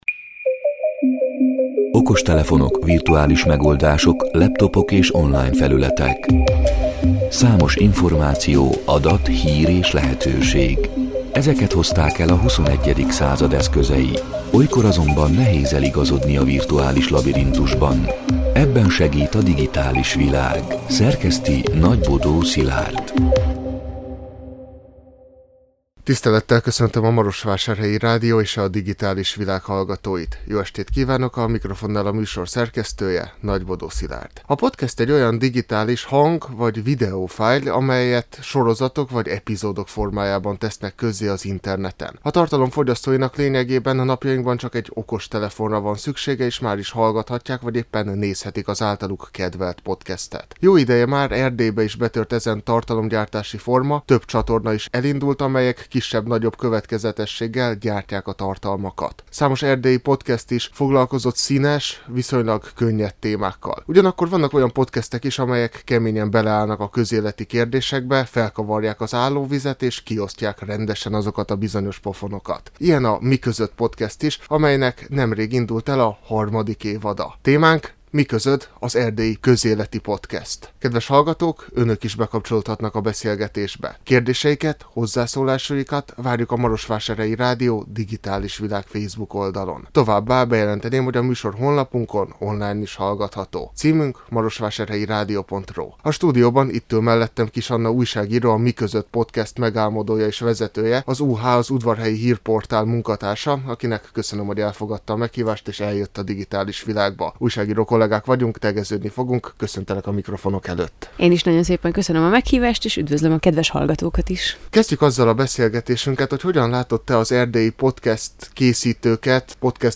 A Marosvásárhelyi Rádió Digitális Világ (elhangzott: 2024. október 22-én, kedden este nyolc órától) c. műsorának hanganyaga: A podcast egy olyan digitális hang-vagy videofájl, amelyet sorozatok vagy epizódok formájában tesznek közzé az interneten.